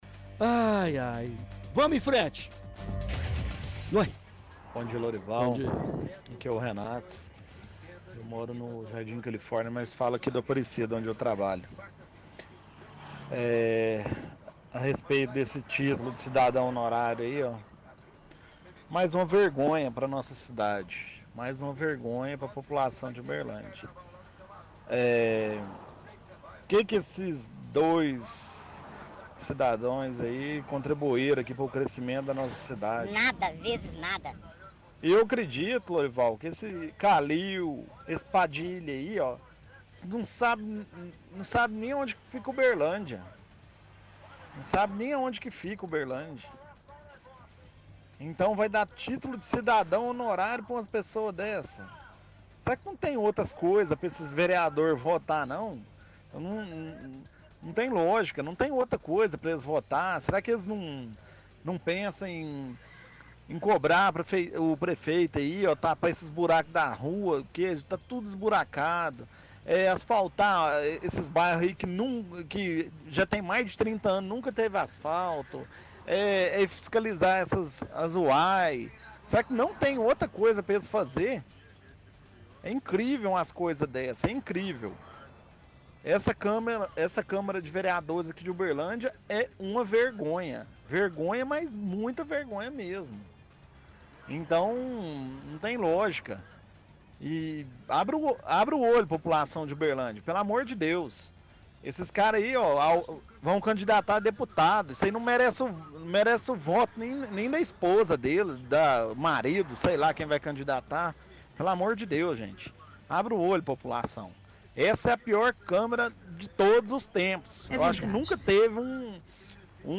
– Ouvinte critica título de cidadão Uberlandense na câmara e questiona se não tem nada melhor para os vereadores fazerem, como fiscalizar UAIs e buracos nas ruas.